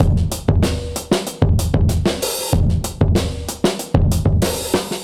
Index of /musicradar/dusty-funk-samples/Beats/95bpm/Alt Sound
DF_BeatA[dustier]_95-01.wav